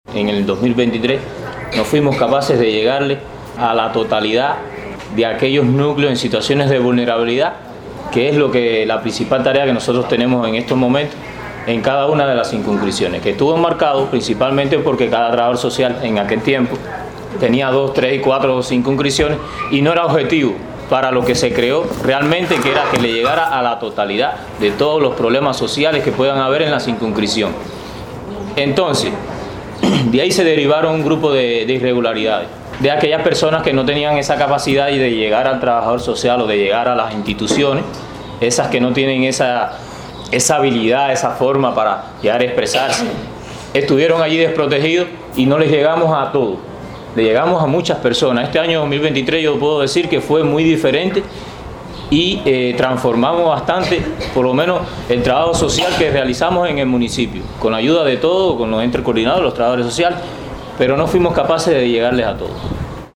Un análisis profundo sobre la labor del trabajador social en la comunidad y la situación del empleo laboral, signaron los debates en el balance del quehacer durante el 2023 de la Dirección Municipal de Trabajo y Seguridad Social este viernes en Jobabo.